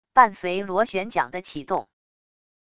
伴随螺旋桨的启动wav音频